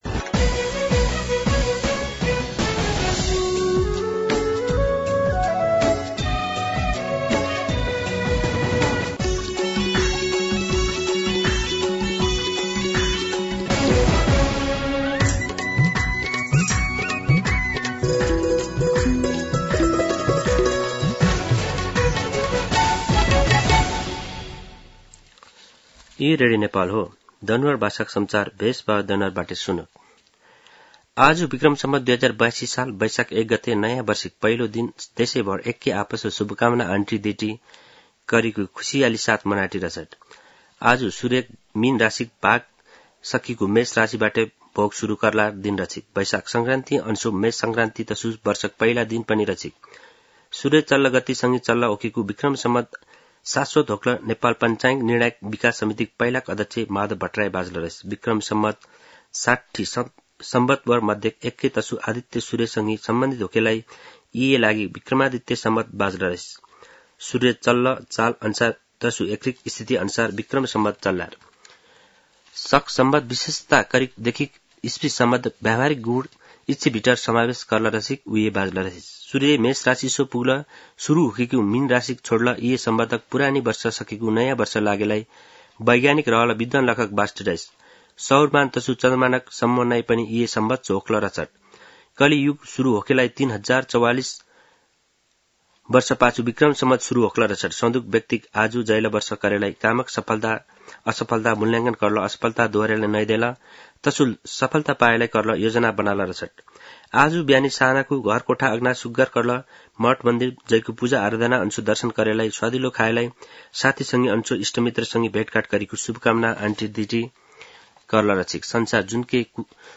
दनुवार भाषामा समाचार : १ वैशाख , २०८२